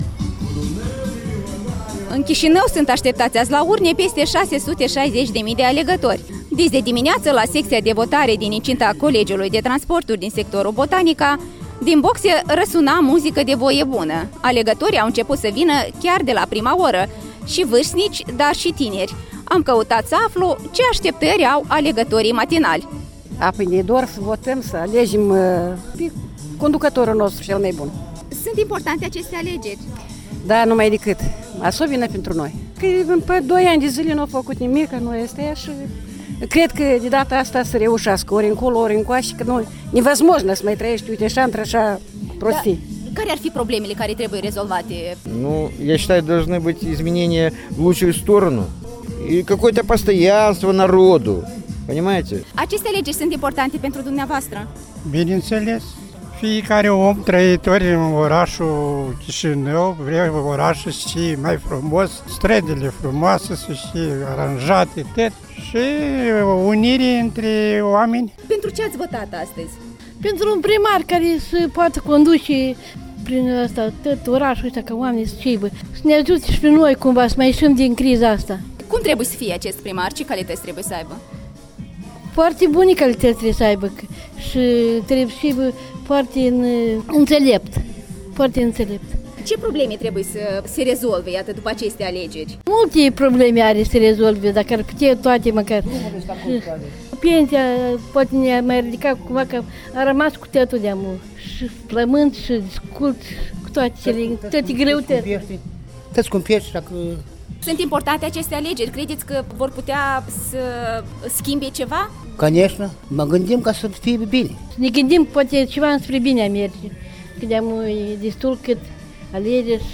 Cu microfonul Europei Libere la o secţie de vot din sectorul Botanica din Chişinău.
Vox populi la o secţie de votare din Chişinău